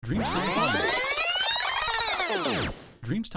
rückspulen